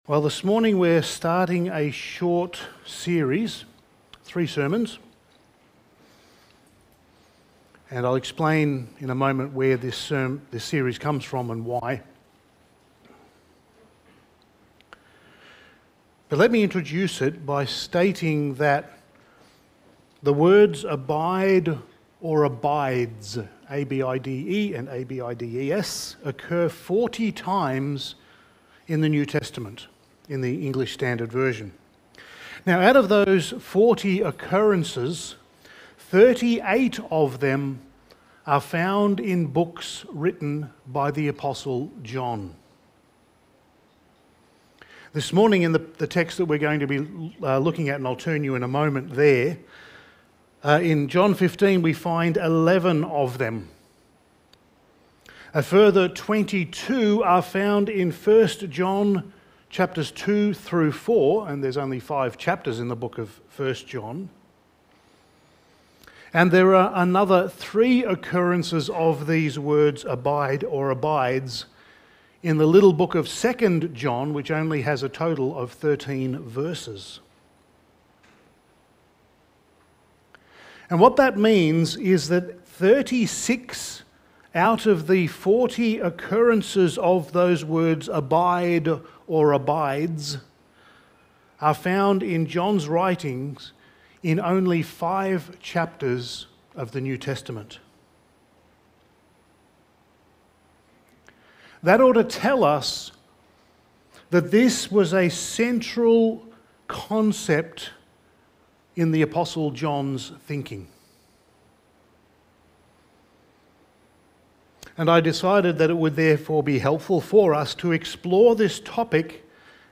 Passage: John 15:1-11 Service Type: Sunday Morning